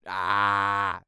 Male_Medium_Vowel_01.wav